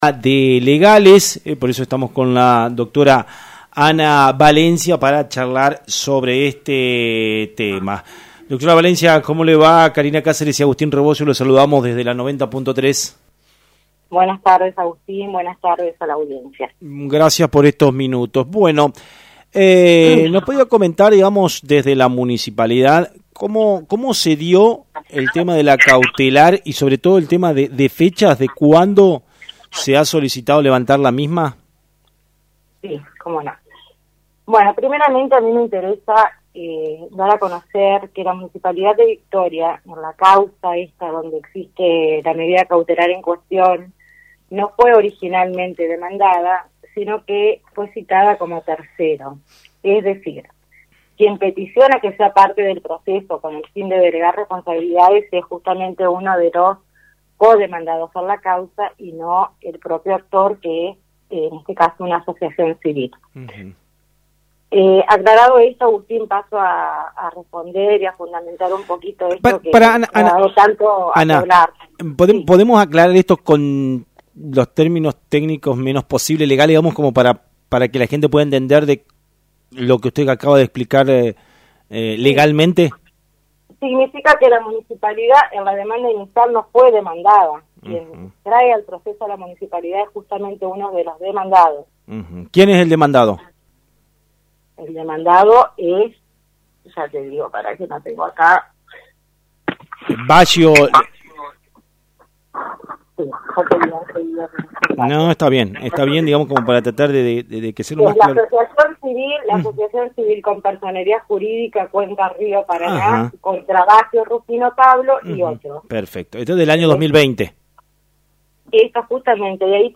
En diálogo con el periodista